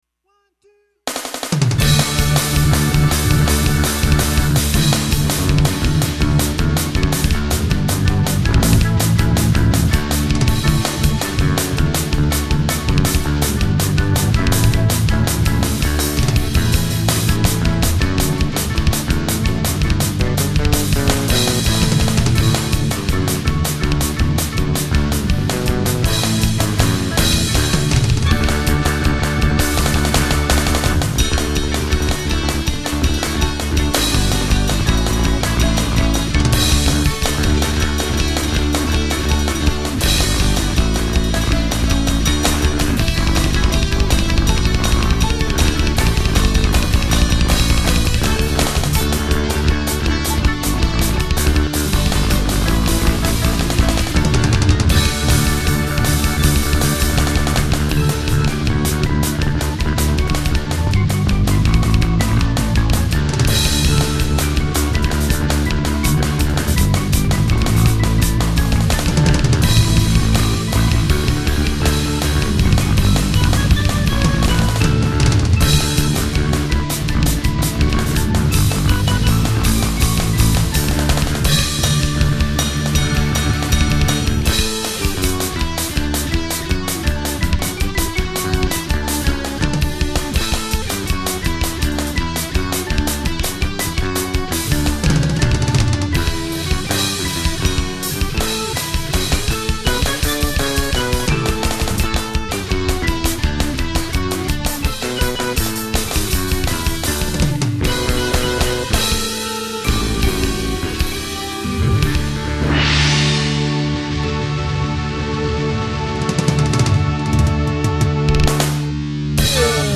Deck the Halls …Punk!
Instrumental